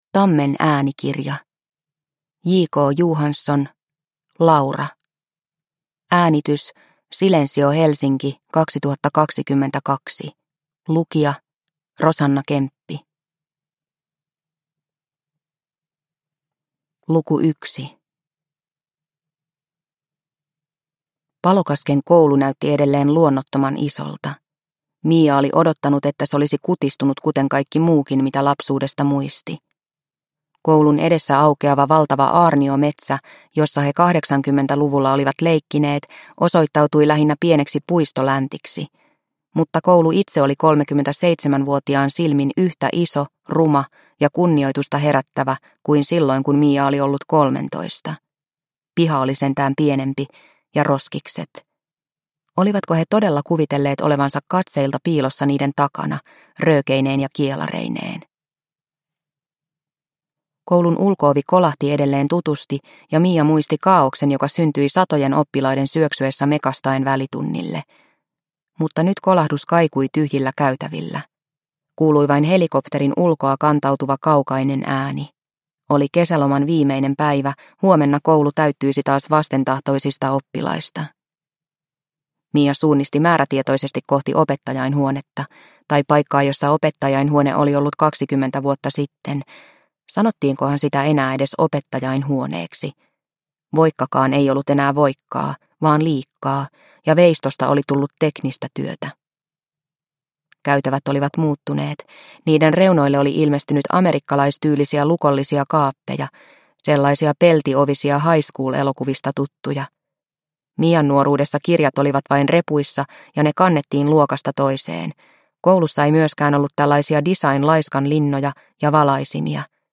Laura – Ljudbok – Laddas ner